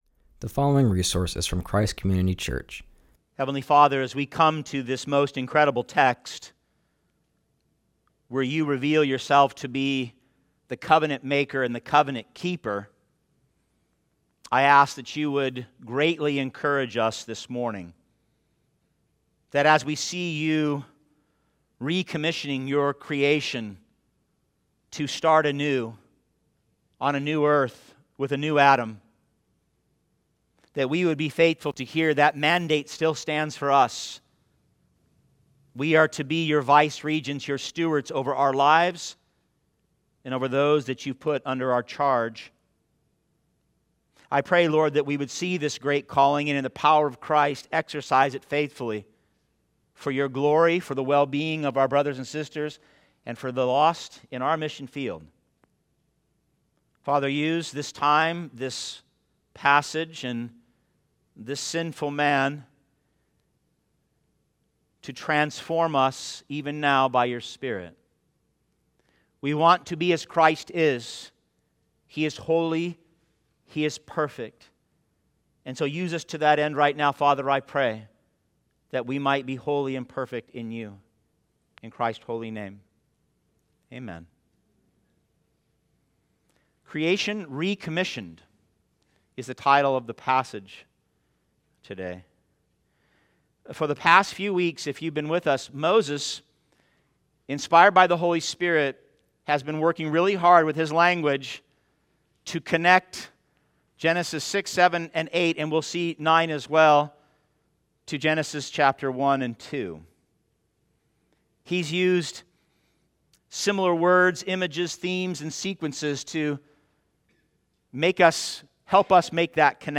continues our series and preaches from Genesis 9:1-17.